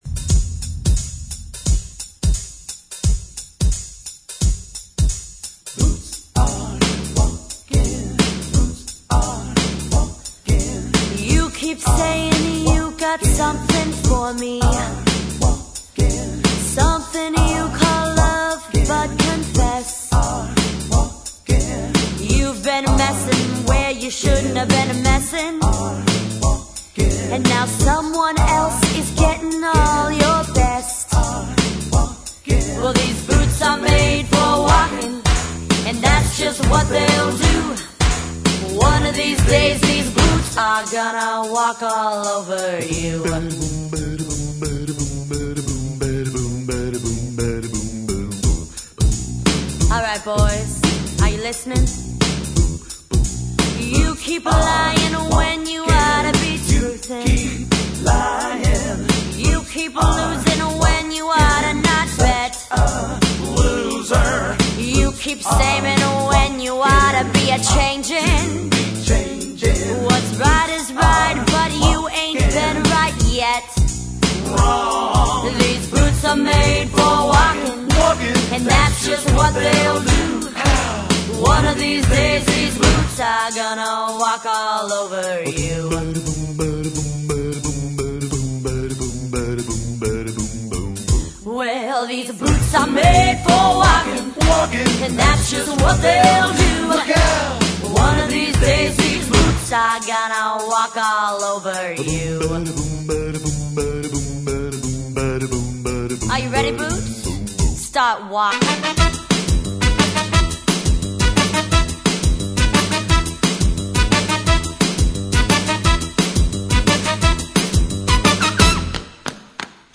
tight vocal harmonies